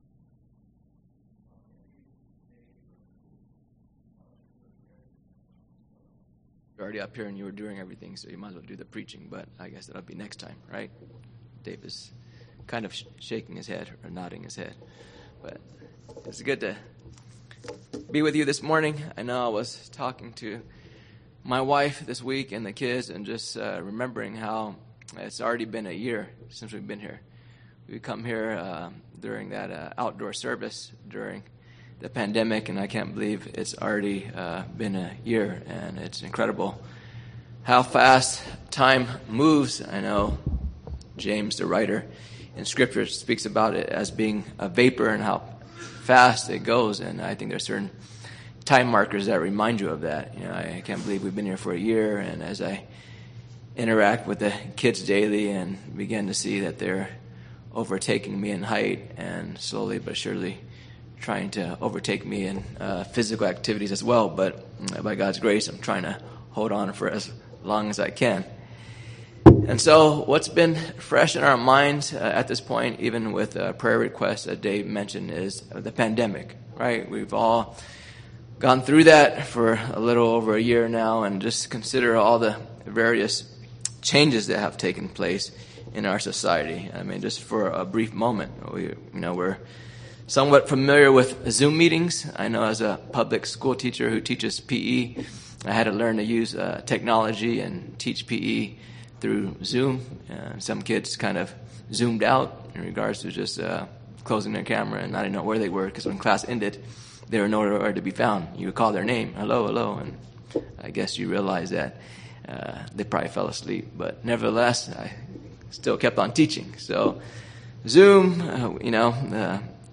Sermon Theme: God has given clear instructions to both slaves and masters in their relation to one another, underscoring their common position as slaves of the Lord Jesus Christ.